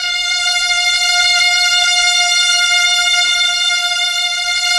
Index of /90_sSampleCDs/Keyboards of The 60's and 70's - CD1/KEY_Chamberlin/STR_Chambrln Str